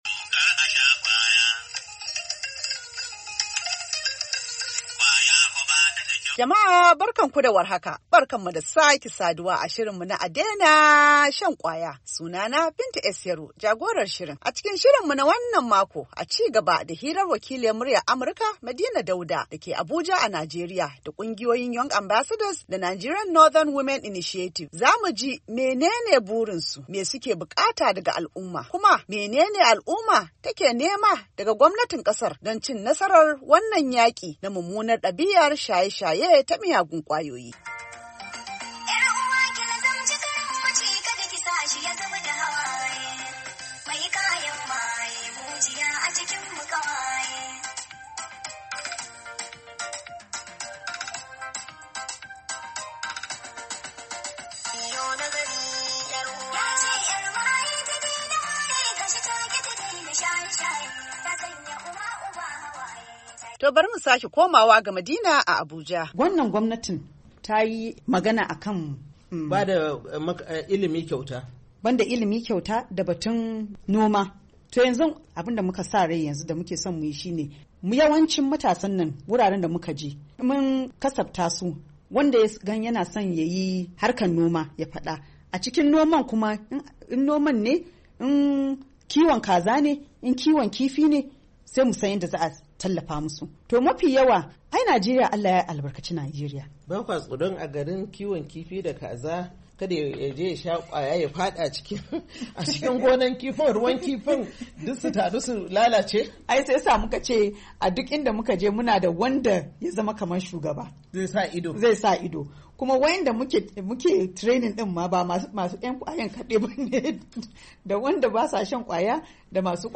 A Daina Shan Kwaya: A ci gaba da hira da Kungiyoyin Young Ambassadors da na Northern Nigerian Women Initiative, za mu ji mene ne burinsu, me suke bukata daga Al’umma, kuma me Al’umma ke bukata daga gwamnatin kasar don shawo kan wannan illar ta shaye-shayen miyagun kwayoyi.